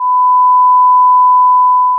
Sound 1 – Sinusoid 1000Hz
Sinusoid-1-1000Hz.wav